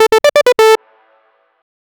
clean futuristic short system alert sound. Very high tech and advanced
clean-futuristic-short-sy-kbifjeey.wav